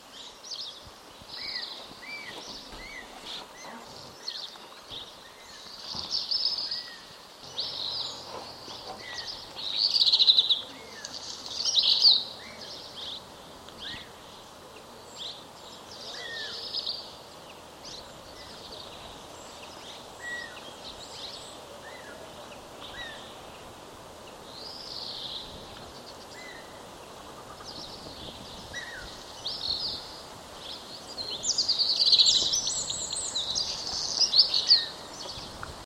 Cigogne noire - Mes zoazos
cigogne-noire.mp3